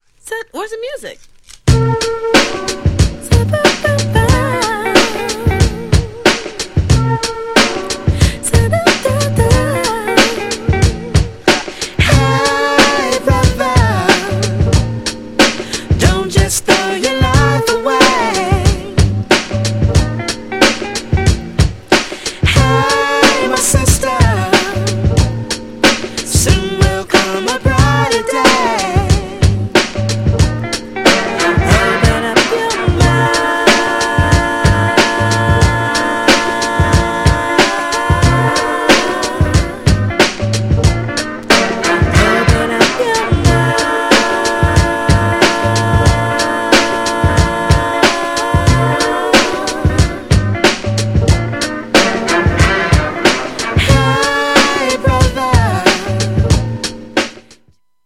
そこに彼女の繊細なボーカルがはまって間違いなし!!
GENRE R&B
BPM 76〜80BPM
# JAZZY_R&B # SMOOTH_R&B # アーバン # 女性VOCAL_R&B # 歌心を感じる